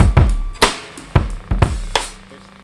Black Box Beat 01.WAV